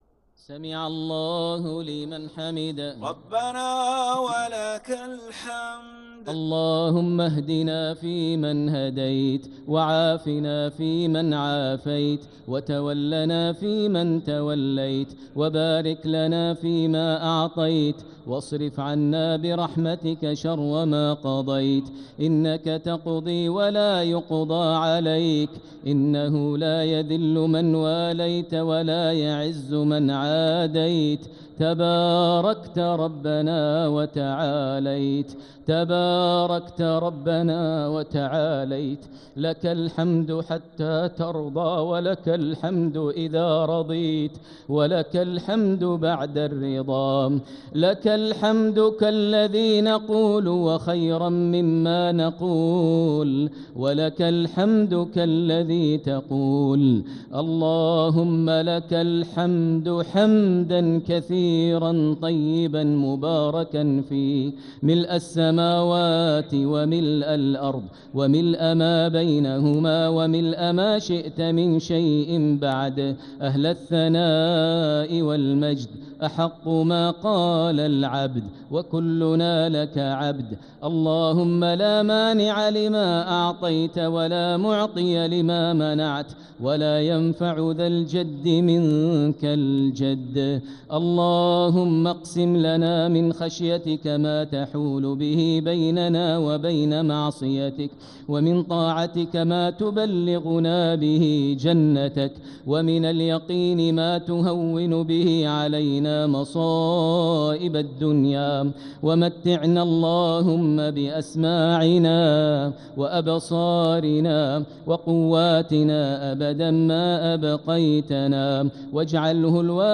دعاء القنوت ليلة 24 رمضان 1446هـ | Dua 24th night Ramadan 1446H > تراويح الحرم المكي عام 1446 🕋 > التراويح - تلاوات الحرمين